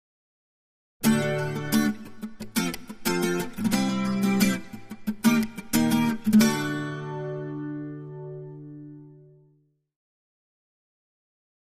Acoustic Guitar - Happy Rhythm Type 1, Version A